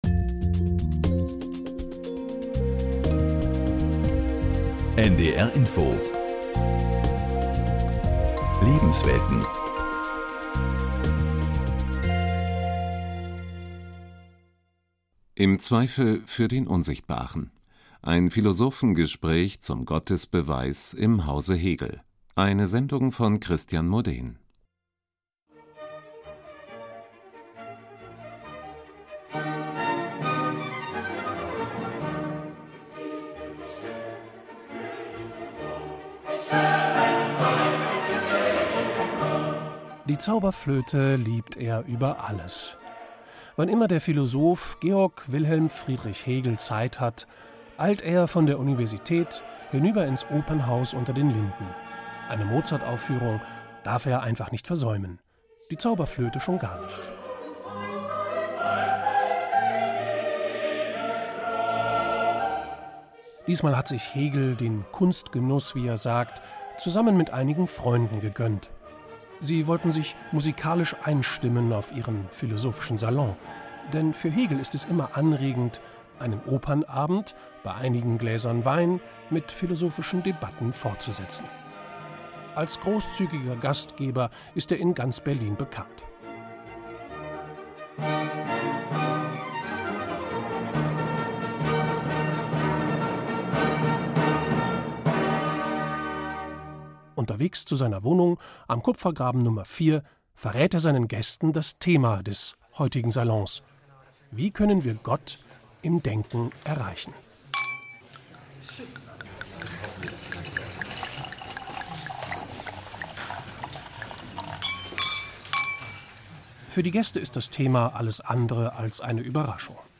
Hörspiel - Steitgespräch über Gottesbeweise (NDR-Info)